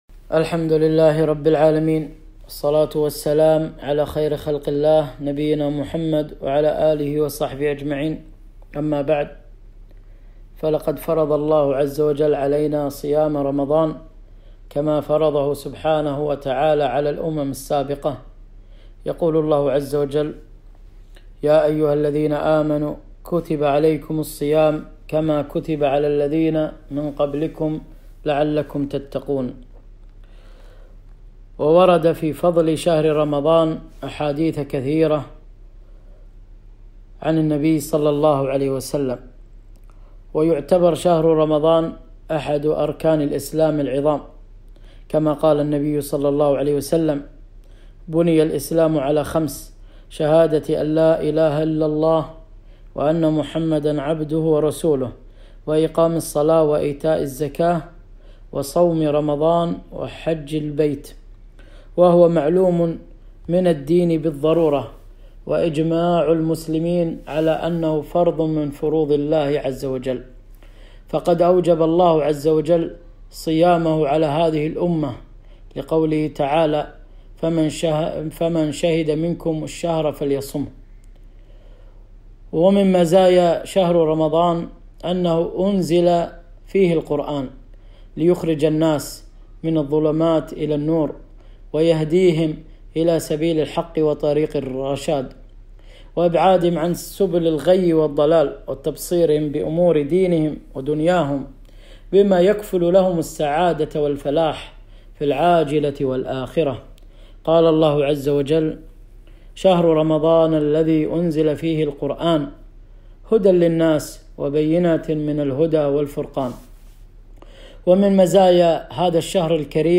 كلمة - فرص رمضانية